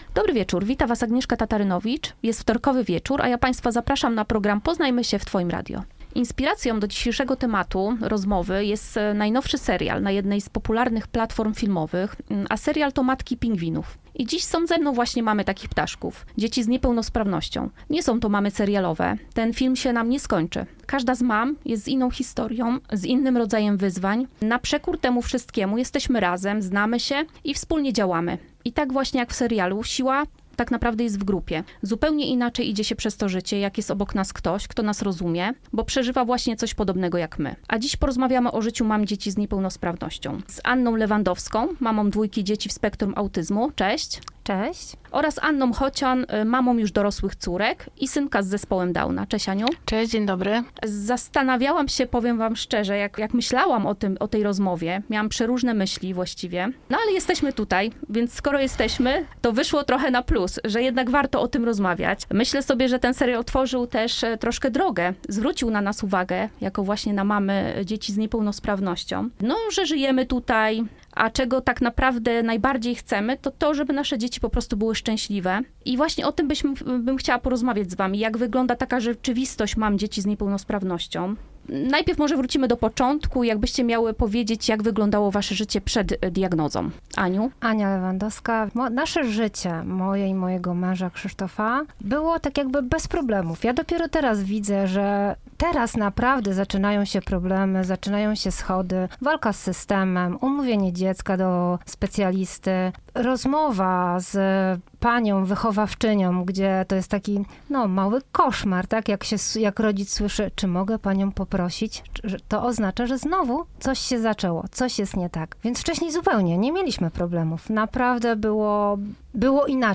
To poruszająca rozmowa pełna szczerości, inspiracji i nadziei.